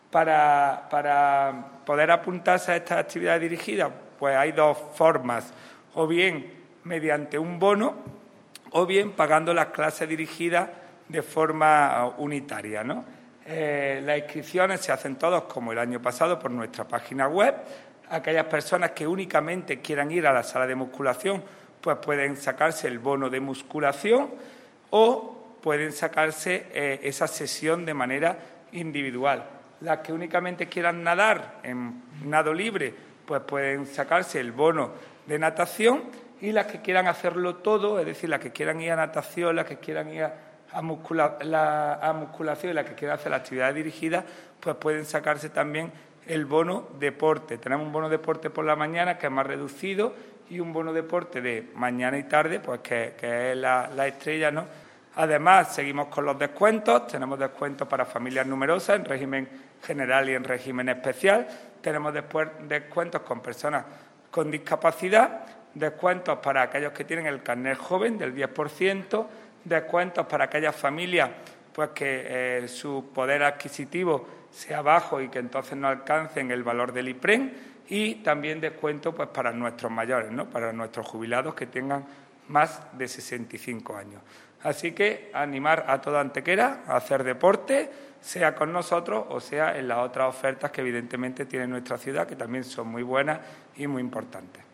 El teniente de alcalde delegado de Deportes del Ayuntamiento de Antequera, Juan Rosas, informa del inicio de las actividades colectivas dirigidas de la nueva temporada 2021/2022 tanto en lo que respecta a las salas multidisciplinares del Pabellón Polideportivbo Fernando Argüelles como en la Piscina Cubierta Municipal.
Cortes de voz